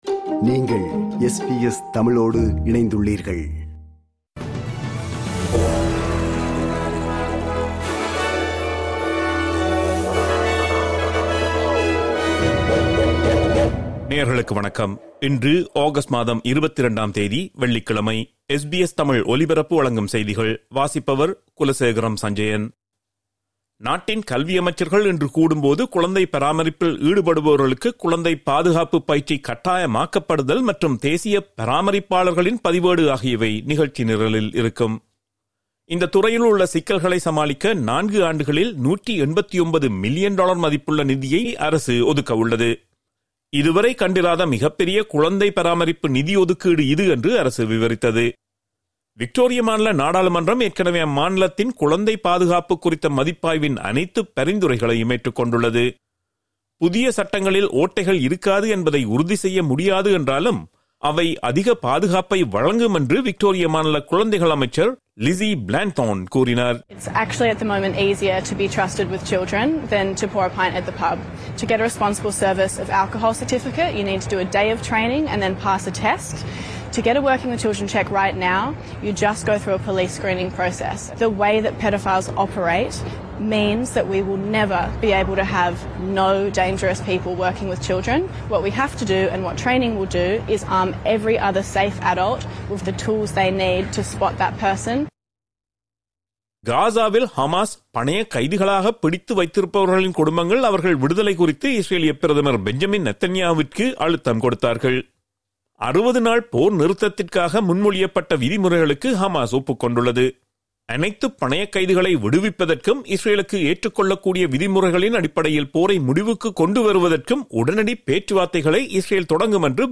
SBS தமிழ் ஒலிபரப்பின் இன்றைய (வெள்ளிக்கிழமை 22/08/2025) செய்திகள்.